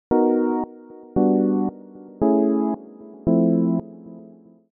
B Maj7 – G Maj7 – A Maj7 – F Maj7
Dit zijn vier Majeur 7 akkoorden, en door deze in volgorde te spelen krijgen de akkoorden een zekere ‘Detroit house’ feel.